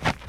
snow-05.ogg